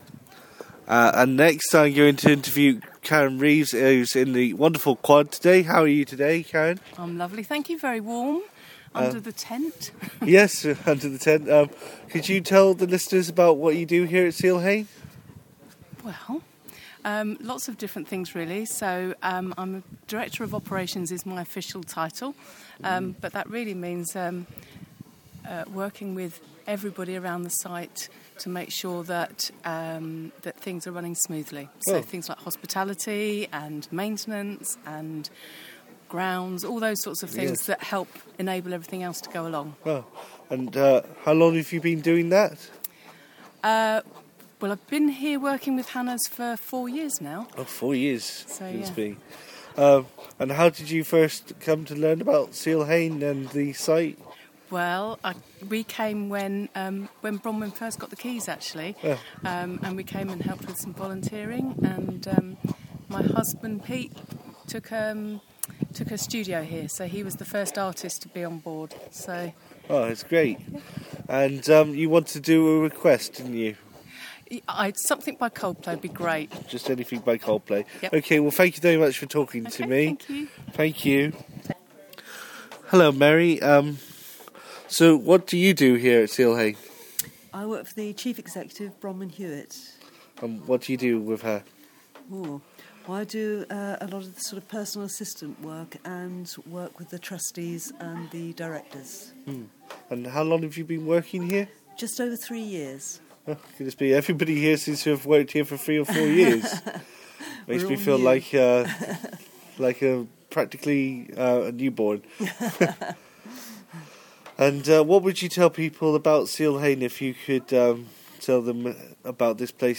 Interviews @ seal hayne